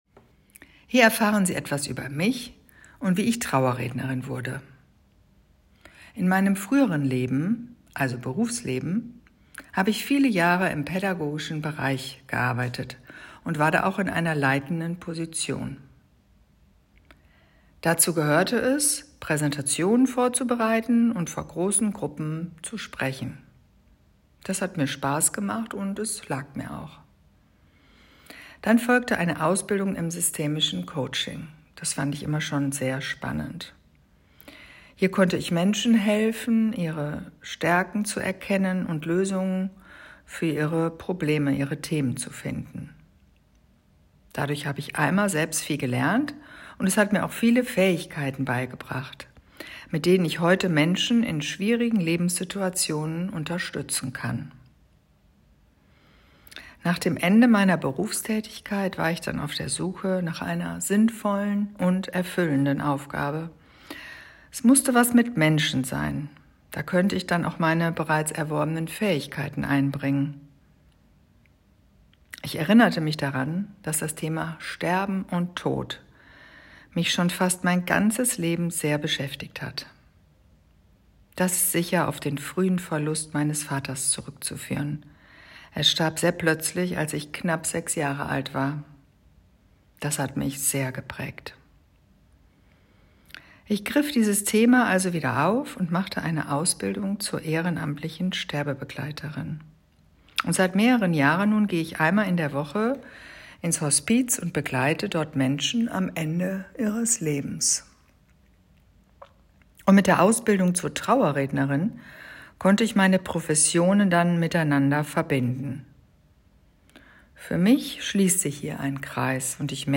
Meine Stimme
Hören Sie sich diese Audio-Datei an, um einen Eindruck meiner Stimme zu gewinnen.